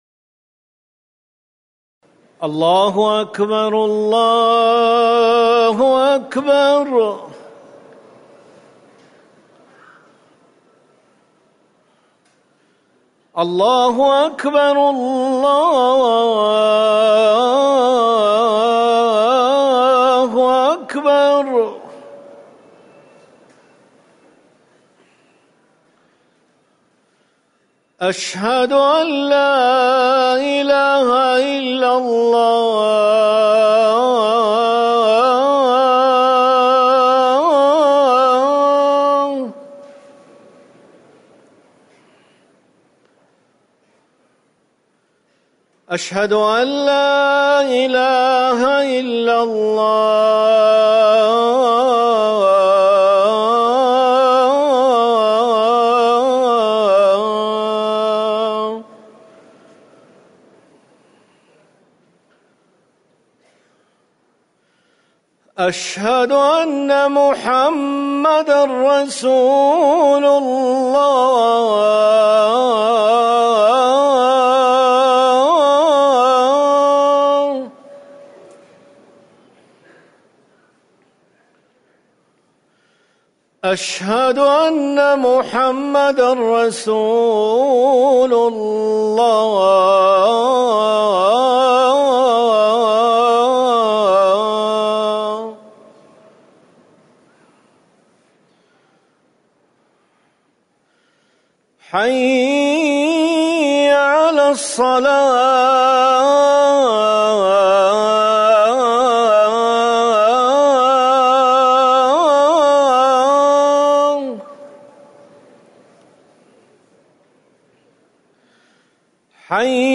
أذان العشاء - الموقع الرسمي لرئاسة الشؤون الدينية بالمسجد النبوي والمسجد الحرام
تاريخ النشر ١٩ محرم ١٤٤١ هـ المكان: المسجد النبوي الشيخ